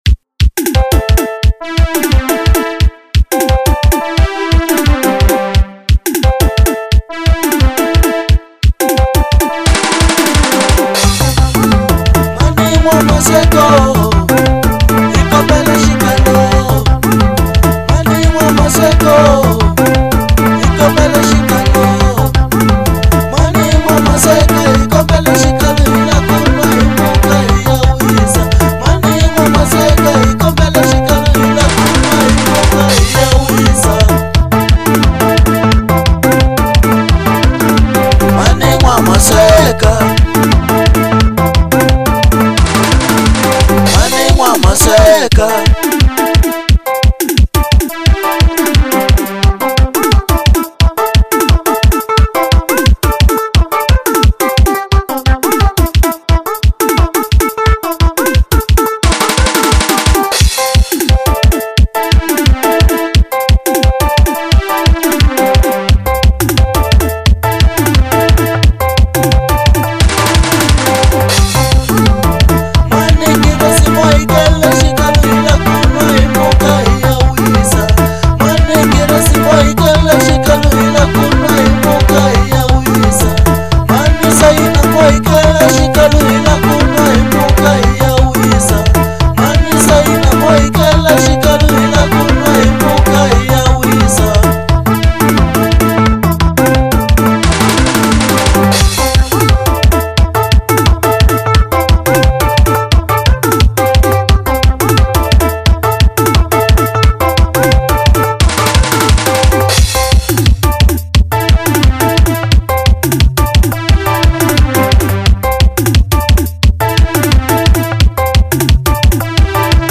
04:29 Genre : Xitsonga Size